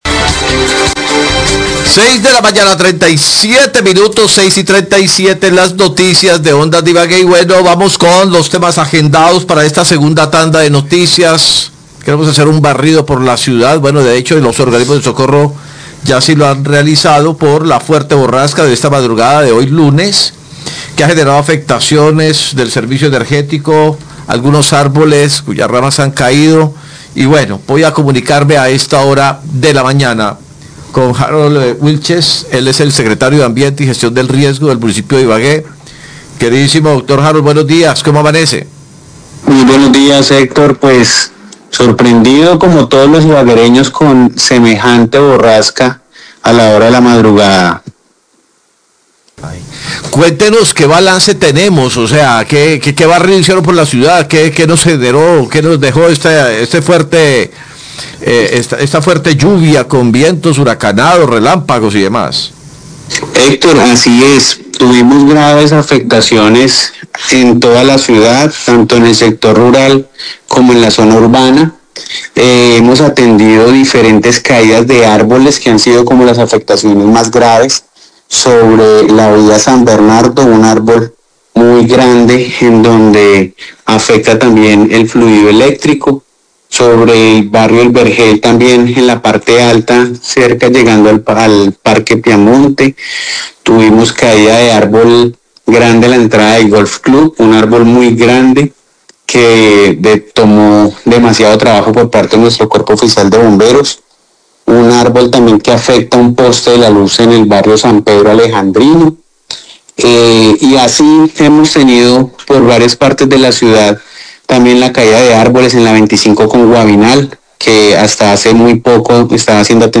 Radio
Luego de la tormenta eléctrica y fuertes lluvias que cayó en horas de la madrugada de este lunes 25 de agosto, Los periodistas de Ondas de Ibagué hablaron con el secretario de Ambiente y Gestión del Riesgo de Ibagué, Harold Wilches, quien señaló que la misma causó múltiples emergencias. La mayoría de los reportes corresponden a caídas de árboles que afectaron la movilidad y el suministro de energía en varios sectores.